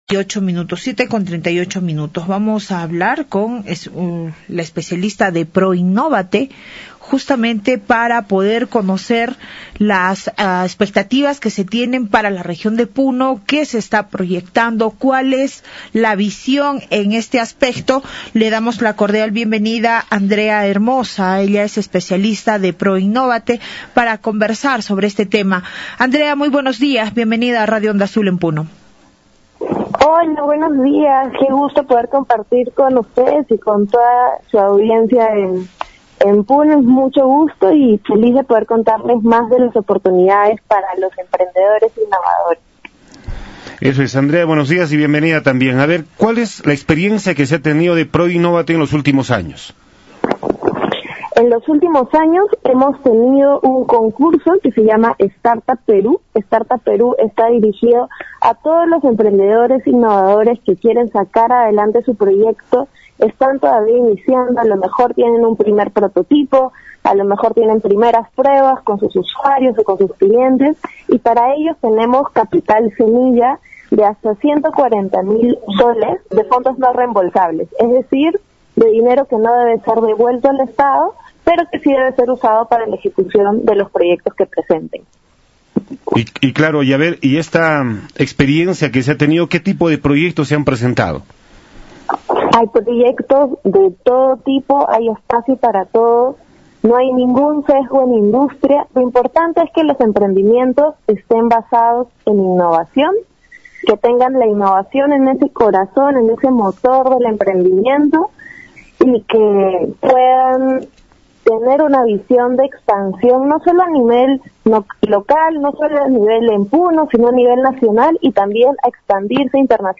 Entrevista
por Radio Onda Azul 95.7 FM - Puno